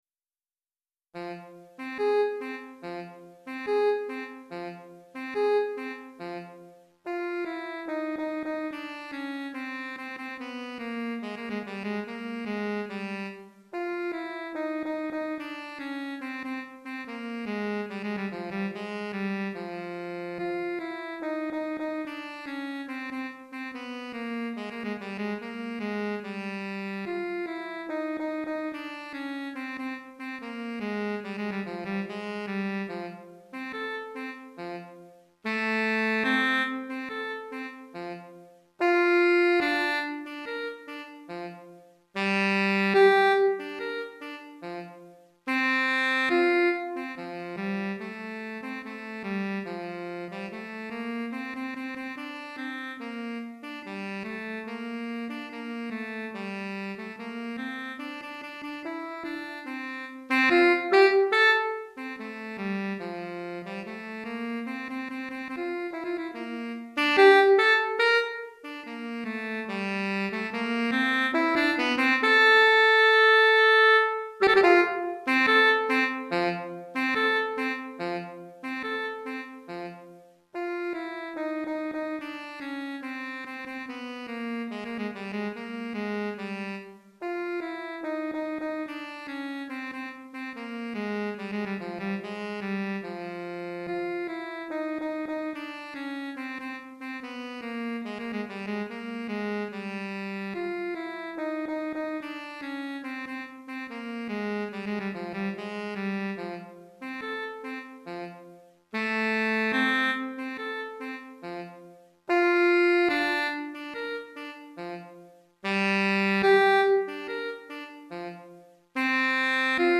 Saxophone Alto Solo